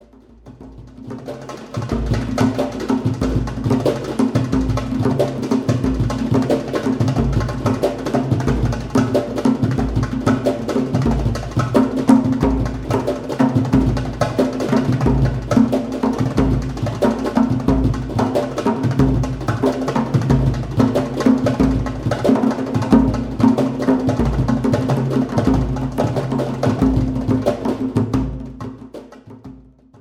Percussion Solo 3